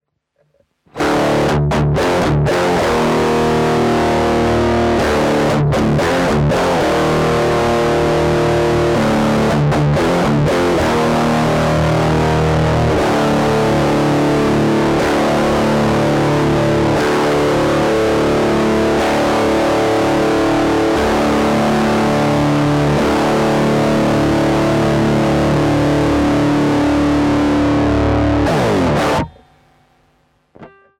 SubDecay Blackstar Klangbeispiele
Alle Klangbeispiele wurden mit einer ESP Eclipse II mit aktiven Humbucker in Drop D aufgenommen.
Mit Ausnahme des ersten Klangbeispiels wurden alle Aufnahmen direkt in eine DI-Box gemacht, damit Du sie in deinen eigenen Amp schicken kannst (Stichwort: Reamping Tutorial).